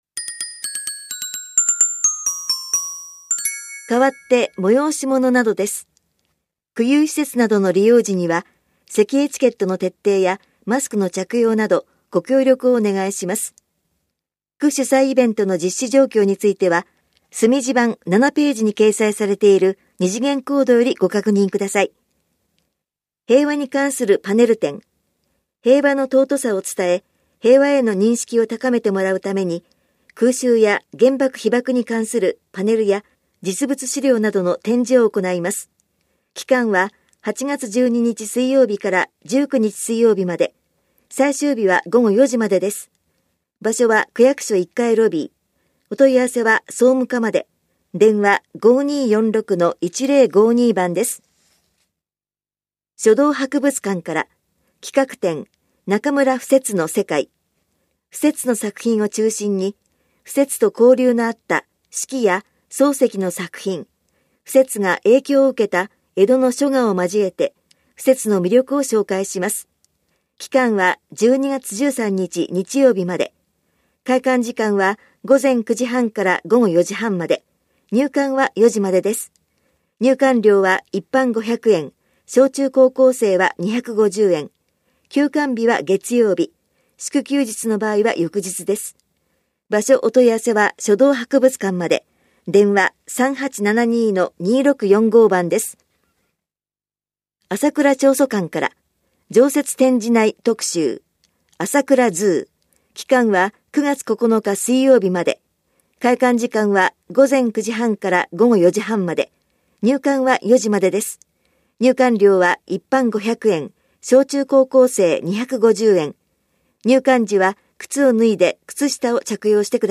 広報「たいとう」令和2年7月20日号の音声読み上げデータです。